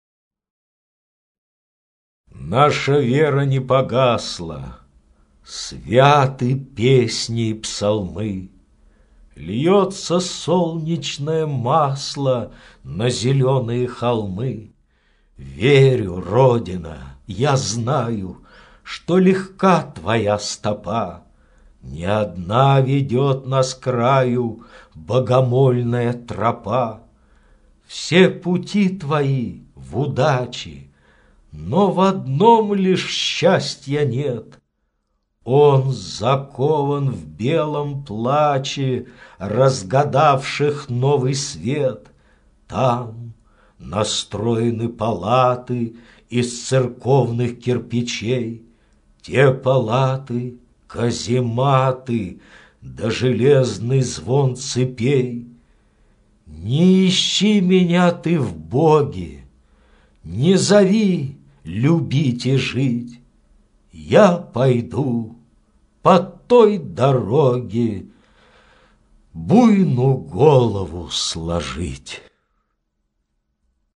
Стихотворение «Наша вера не погасла...» — для самостоятельного чтения детьми. Прослушивание аудиозаписи этого стихотворения с сайта «Старое радио».